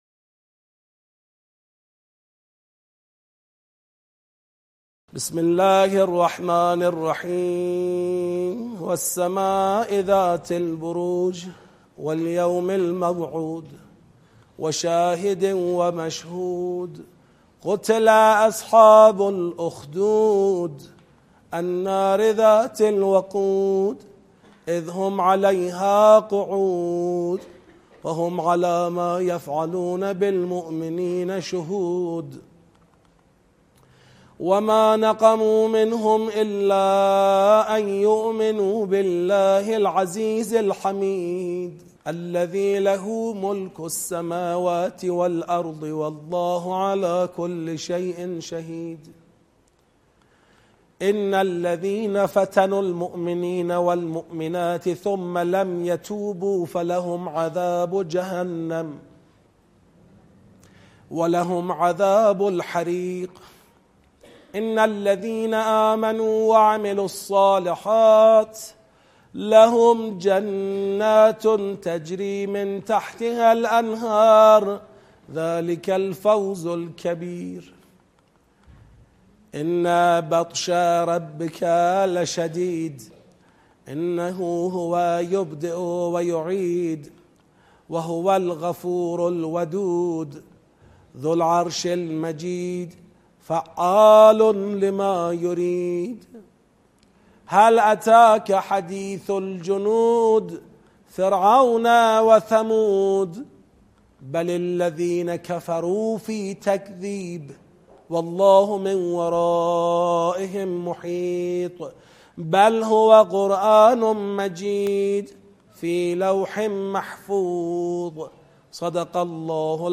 قرائت تدبری سوره بروج
این قرائت مربوط به دوره آموزش سطح یک تدبر در قرآن کریم است که پاییز ۹۸ در شهرک شهید محلاتی برگزار شده است.
09-1-قرائت-تدبری-سوره-بروج.mp3